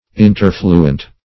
Search Result for " interfluent" : The Collaborative International Dictionary of English v.0.48: Interfluent \In*ter"flu*ent\, Interfluous \In*ter"flu*ous\, a. [L. interfluens, p. pr., and interfluus.